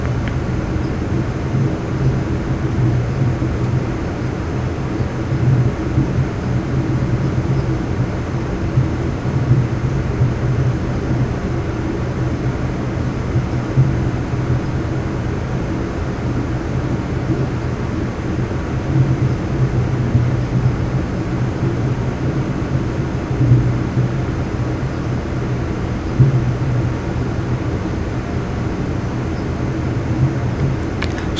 It appears that the rumble is somewhere close to 130Hz.
View attachment Noise Profile.mp3. .